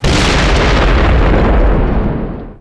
DefaultRobotExplode2b.wav